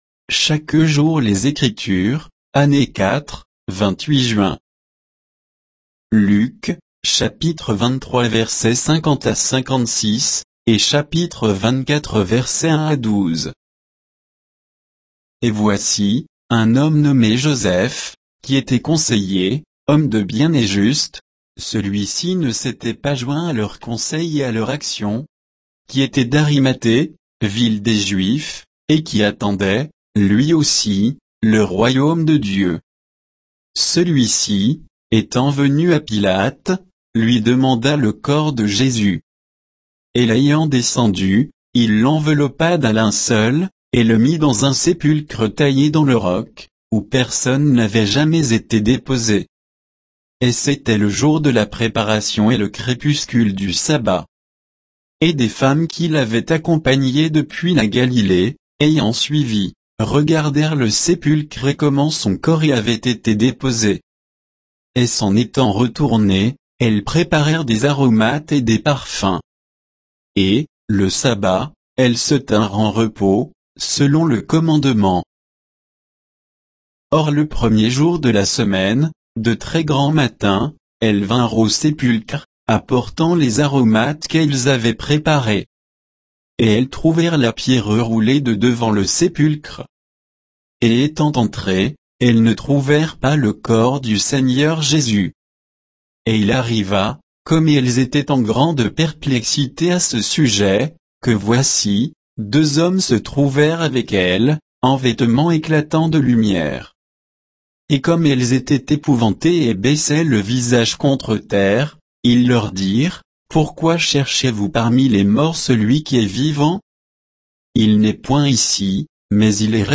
Méditation quoditienne de Chaque jour les Écritures sur Luc 23